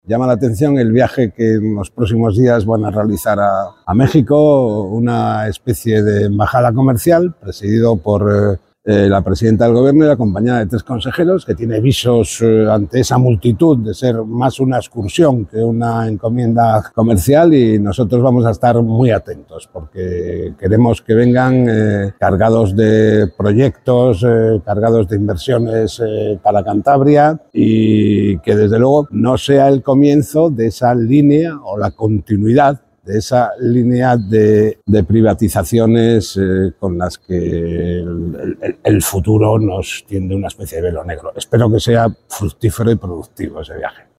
Ver declaraciones de Francisco Javier López Marcano, diputado y portavoz del Partido Regionalista de Cantabria en materia industria.